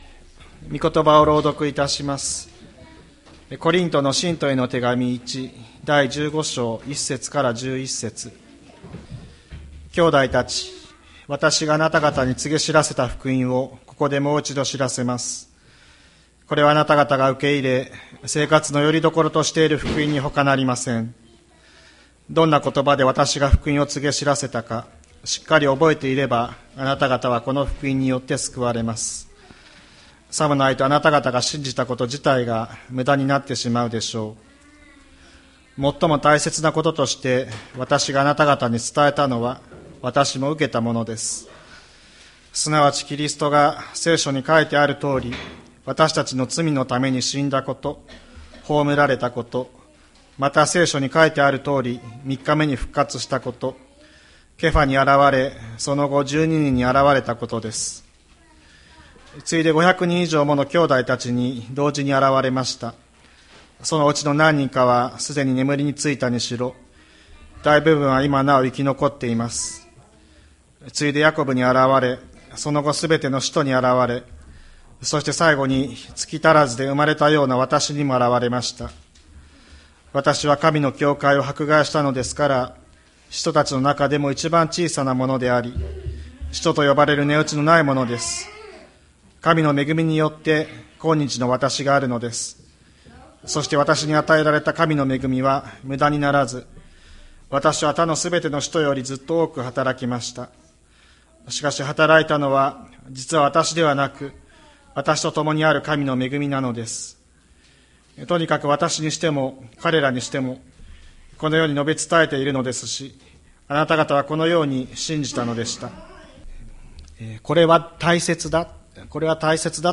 2024年04月21日朝の礼拝「復活、最も伝えたいこと」吹田市千里山のキリスト教会
千里山教会 2024年04月21日の礼拝メッセージ。